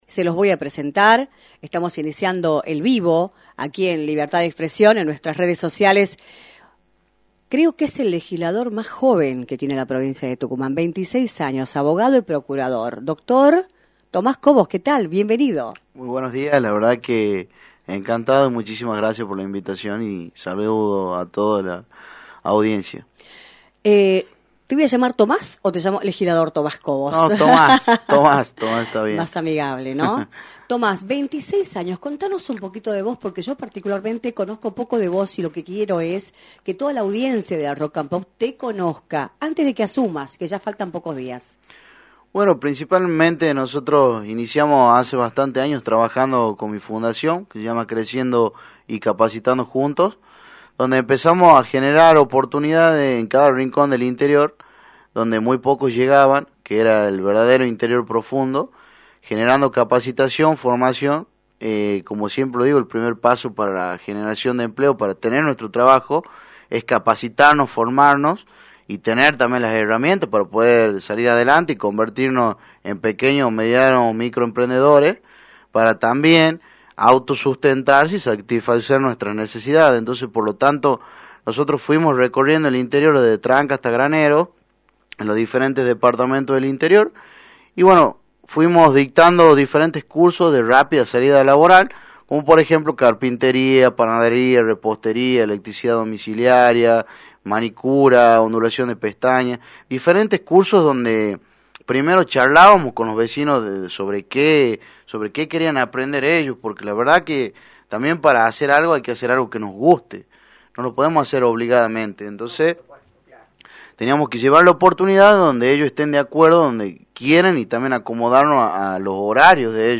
Tomás Cobos, Abogado, Procurador, impulsor de una de las fundaciones más importantes del interior y Legislador electo, visitó los estudios de “Libertad de Expresión”, por la 106.9, para analizar la situación política y social del país, en la previa de las elecciones nacionales del próximo 22 de octubre.